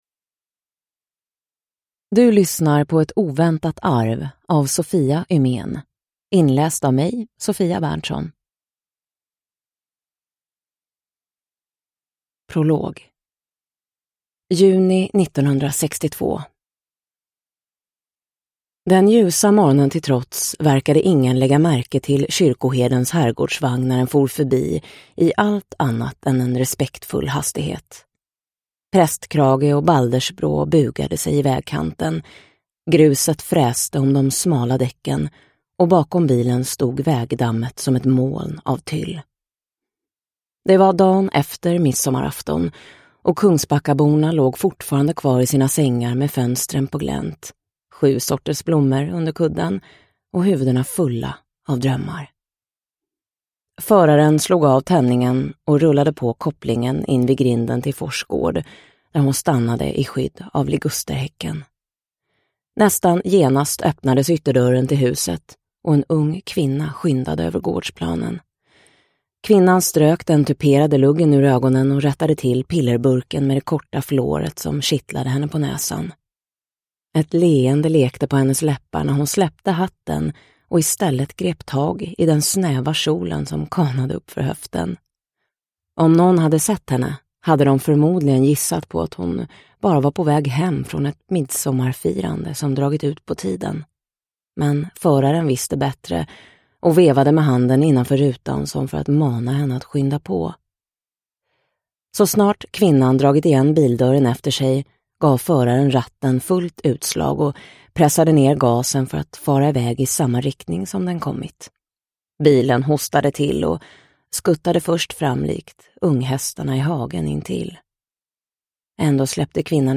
Ett oväntat arv – Ljudbok – Laddas ner